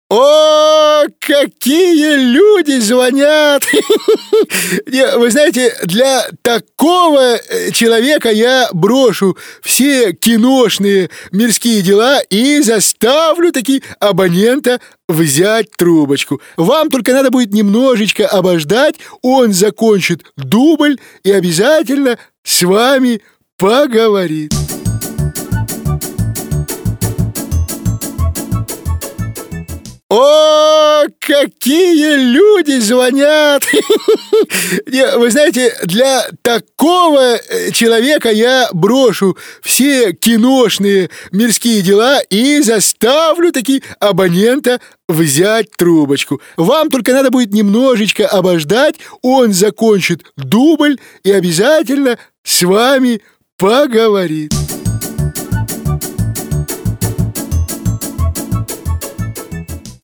Parodiya_na_Mihalkova.mp3